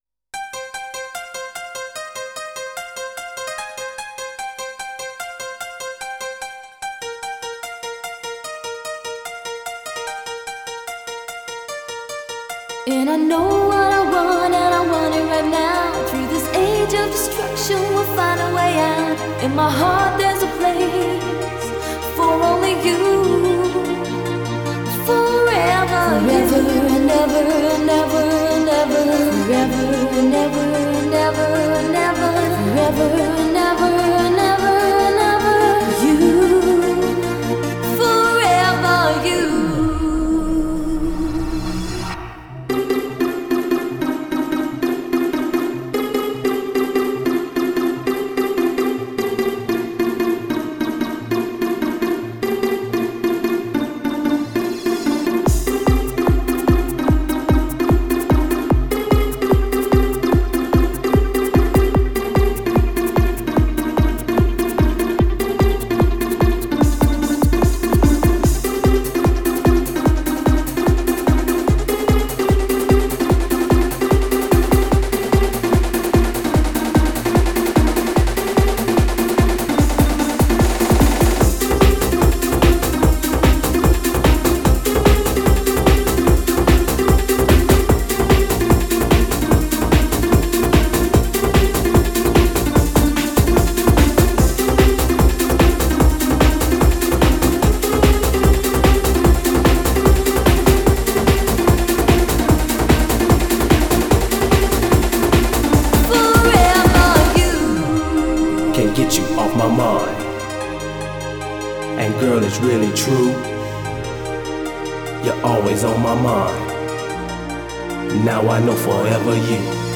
Genre: Euro-House.